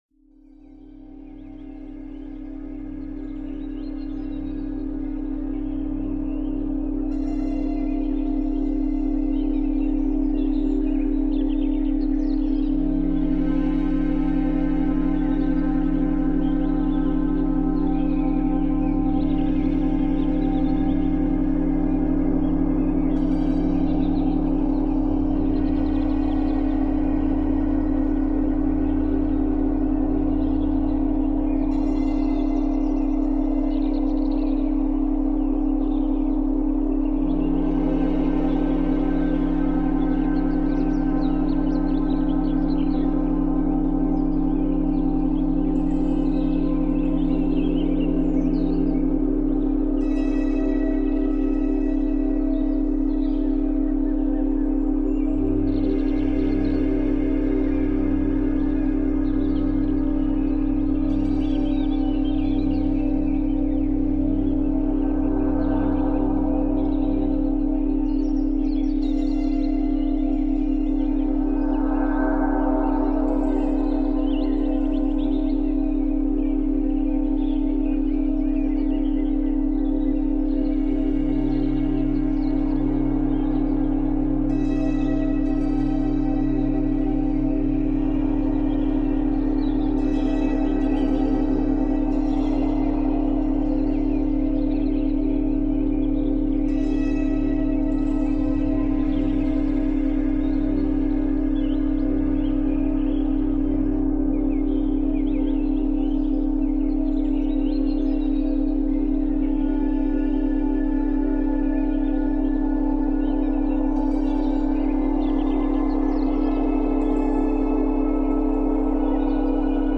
Mozart Violon : Étude Sereine